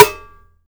Snares
SNARE.83.NEPT.wav